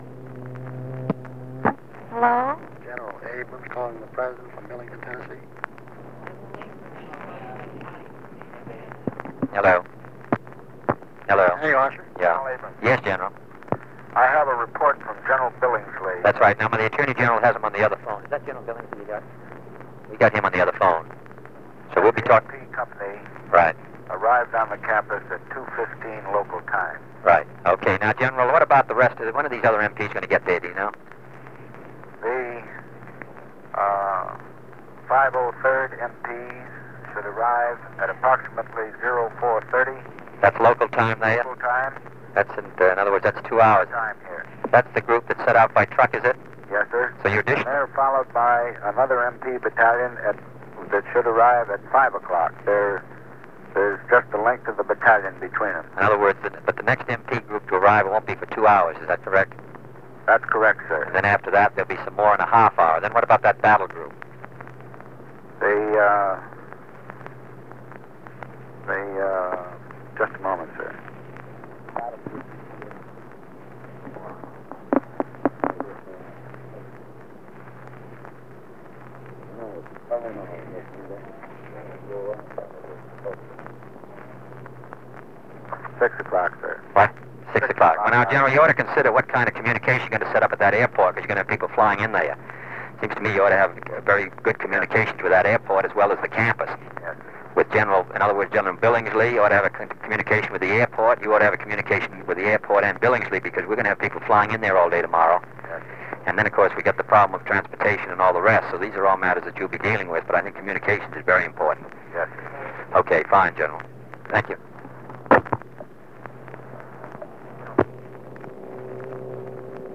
Conversation with Creighton Abrams
Secret White House Tapes | John F. Kennedy Presidency Conversation with Creighton Abrams Rewind 10 seconds Play/Pause Fast-forward 10 seconds 0:00 Download audio Previous Meetings: Tape 121/A57.